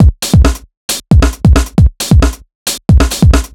Brock Break 135.wav